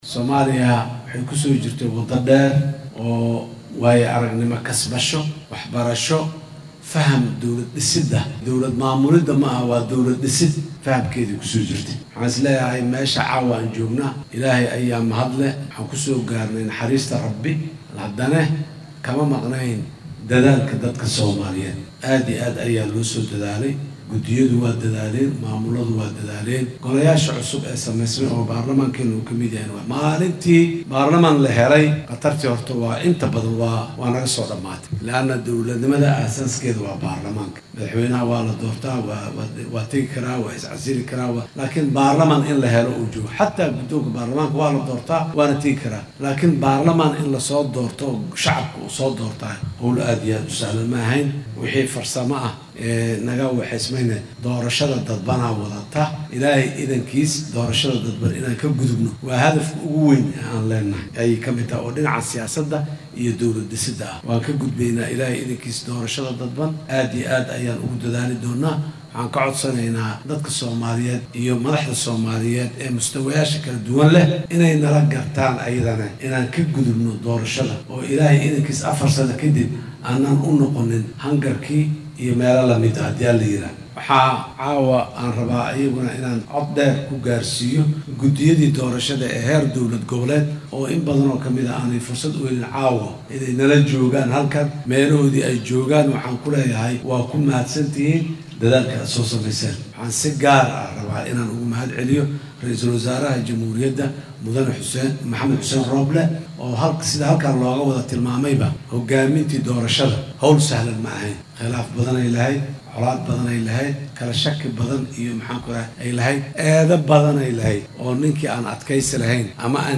Madaxweynaha Soomaaliya, Xasan Sheekh Maxamuud oo ka qayb galay munaasabad lagu bogaadinayay Guddiyadii ka soo shaqeeyay Doorashadii dalkaasi ayaa ka hadlay